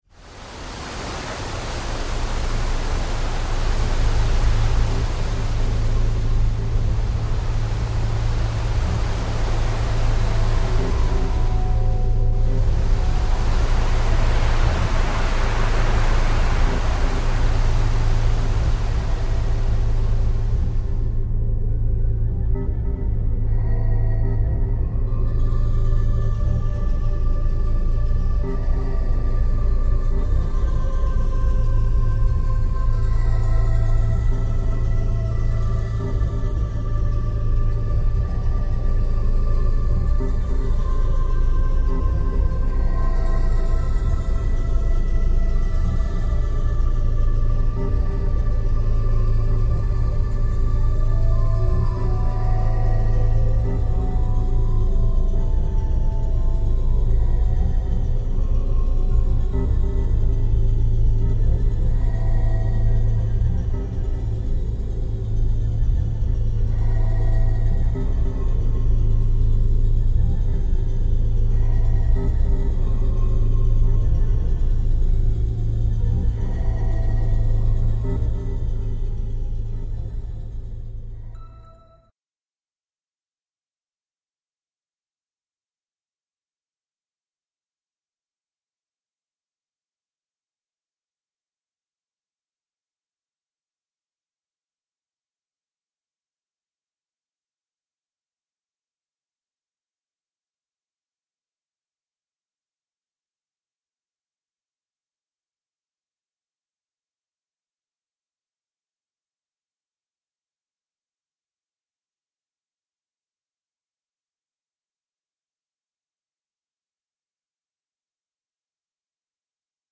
scary wind mp3